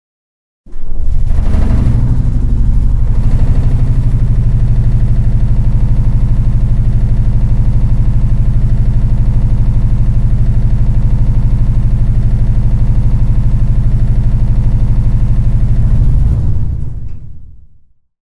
Звуки кондиционера
Шум неисправного кондиционера